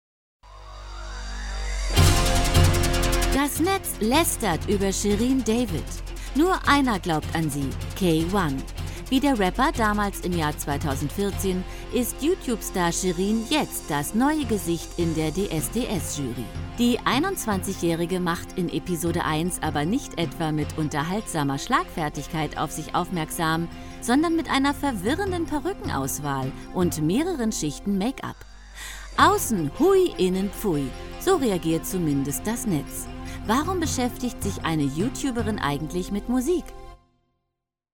Werbung TV LOreal (warm, selbstbewusst, geheimnisvoll)